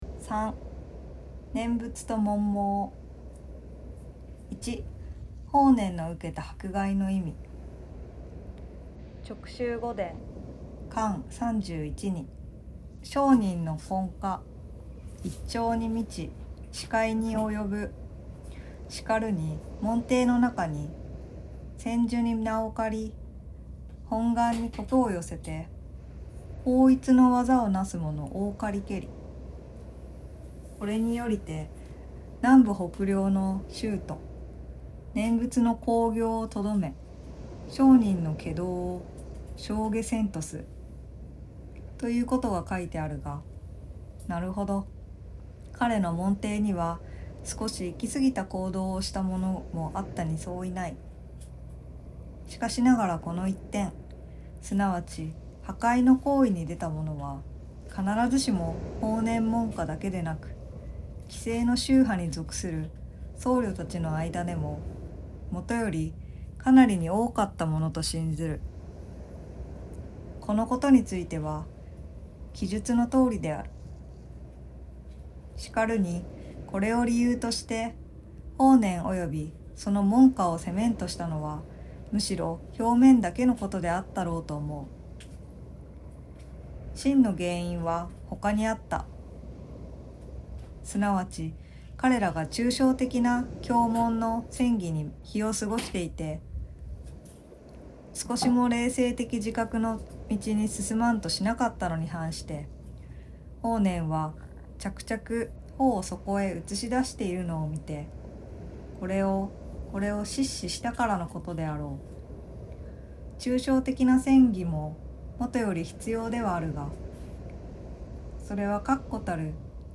心を豊かにする朗読。